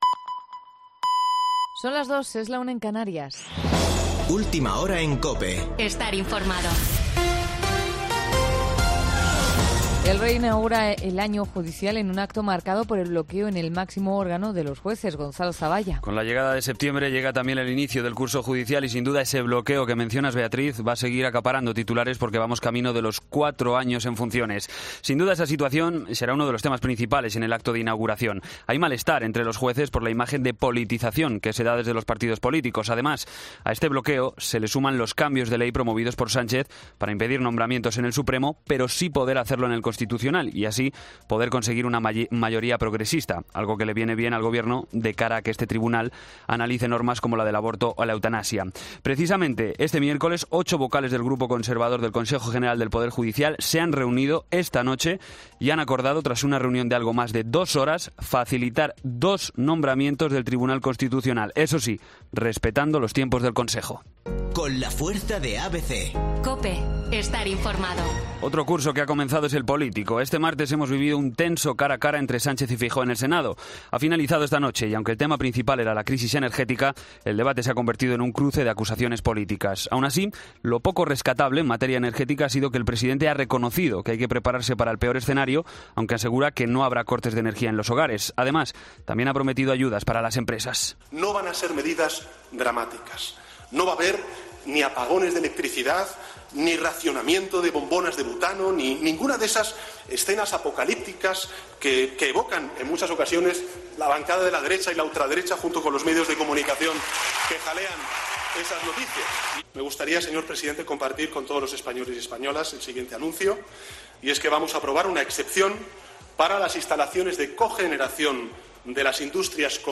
Boletín de noticias de COPE del 7 de septiembre de 2022 a las 02:00 horas